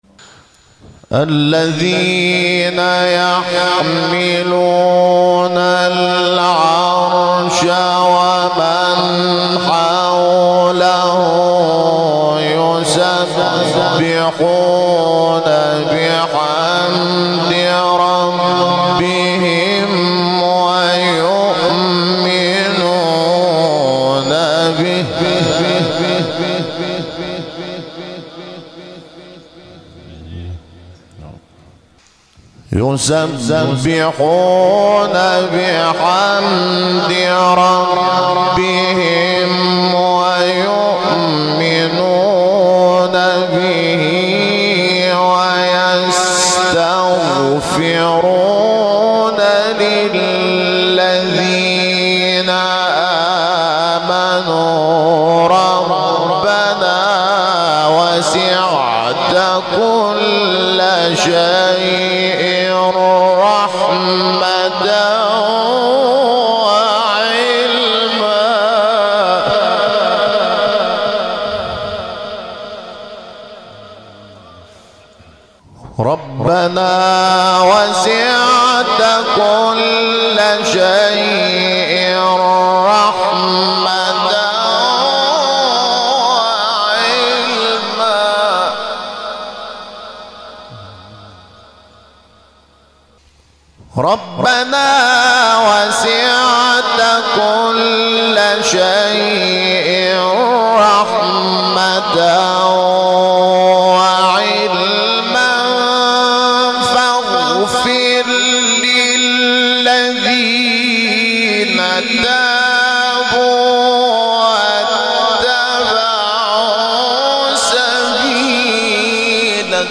تلاوت 7 غافر محمود شحات زیبا | نغمات قرآن
مقام: رست * بیات